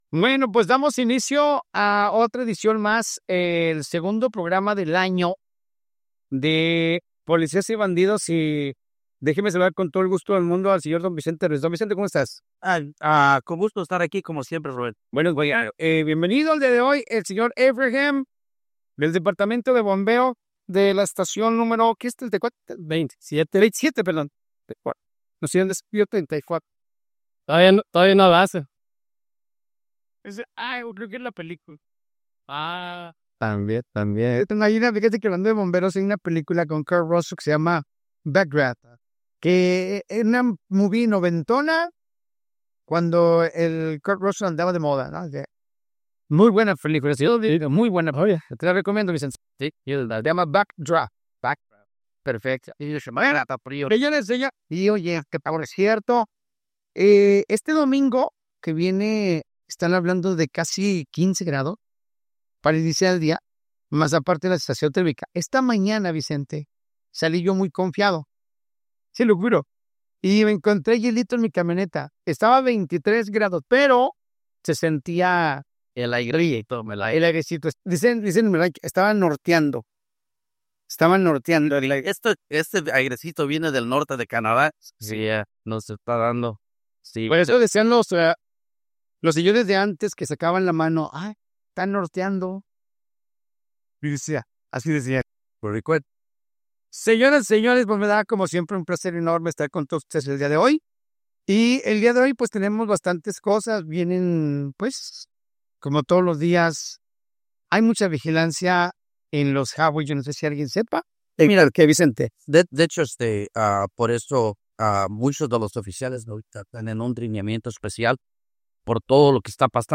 Los oficiales enfatizaron la importancia de respetar las leyes de tránsito, en especial los límites de velocidad en pueblos pequeños, donde las infracciones pueden resultar en multas y, en algunos casos, arrestos.